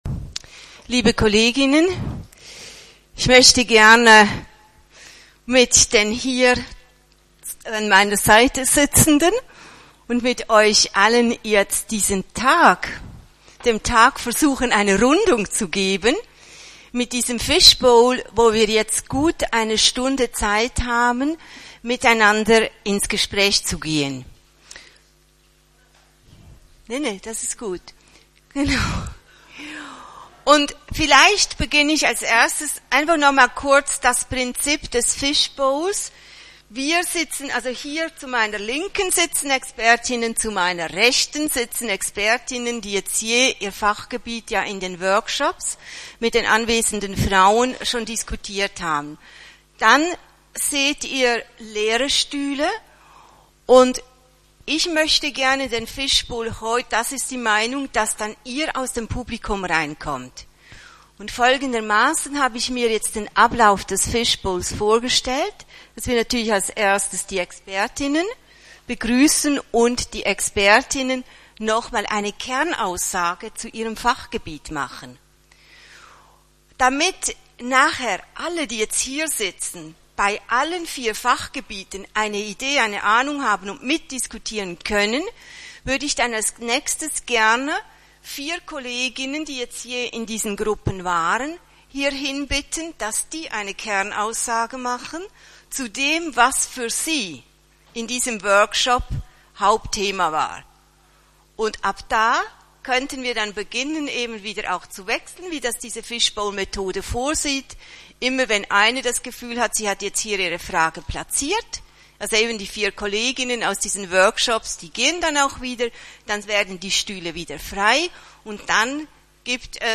Geführte Diskussionsrunde: Die selbstbestimmte Patientin – Lachesis e.V.
auf dem LACHESIS Kongress 2017